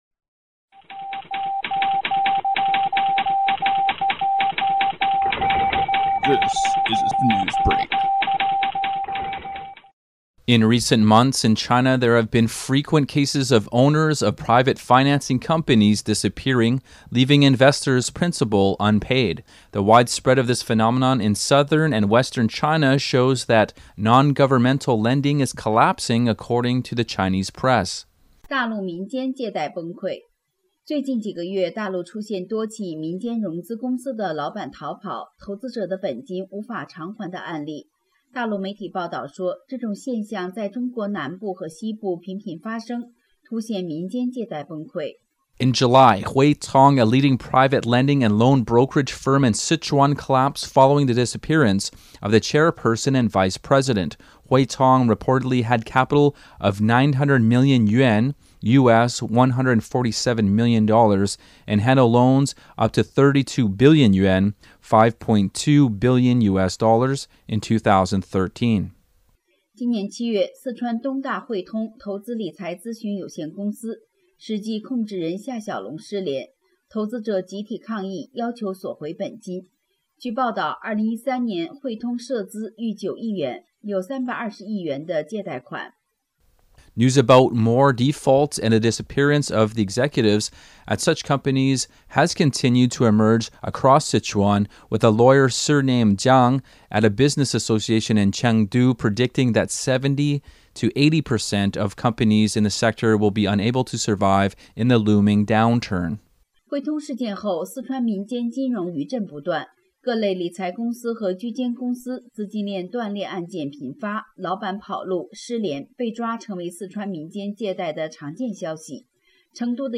Type: News Reports
128kbps Mono